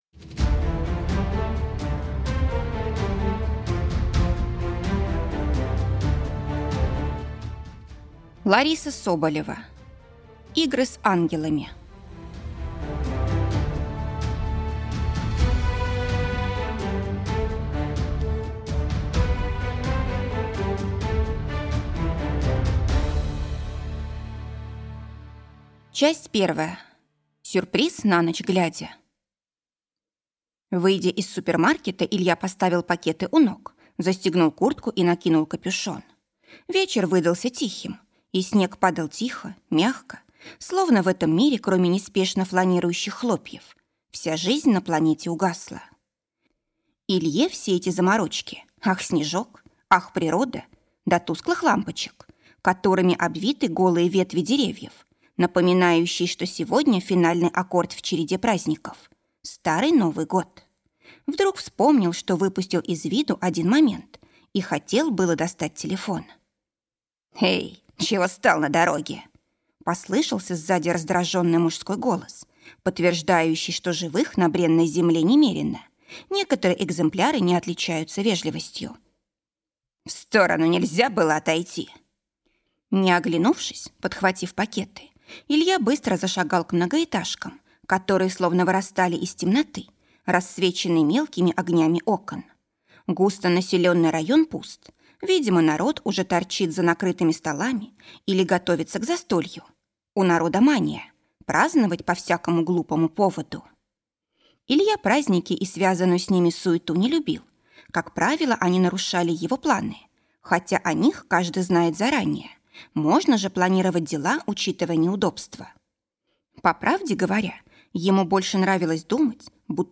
Аудиокнига Игры с ангелами | Библиотека аудиокниг